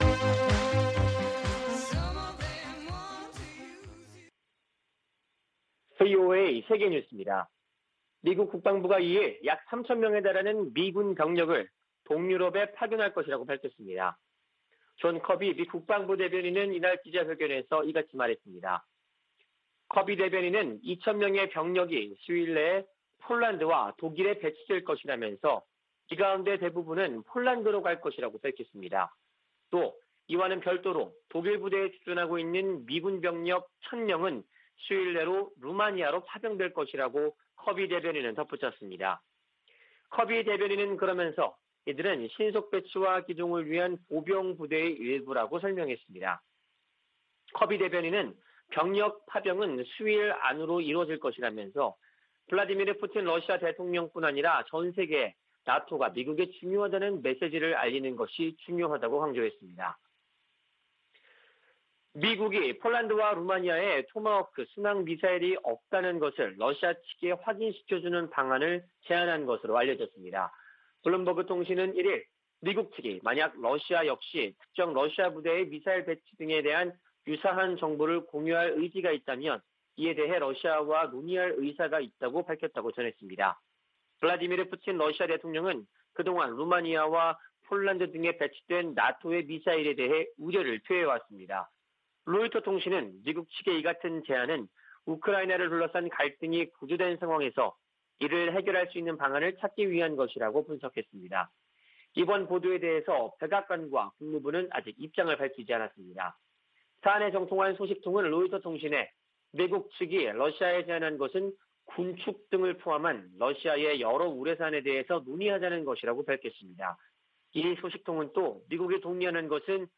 VOA 한국어 아침 뉴스 프로그램 '워싱턴 뉴스 광장' 2021년 2월 3일 방송입니다. 미 국무부 대북특별대표가 한일 북 핵 수석대표들과 북한의 최근 중거리 탄도미사일 발사를 논의하며 도발을 규탄했습니다. 미국이 북한 미사일 발사에 대응한 유엔 안보리 긴급 회의를 요청했습니다. 유엔이 북한의 중거리 탄도미사일 발사를 모라토리엄 파기로 규정했습니다.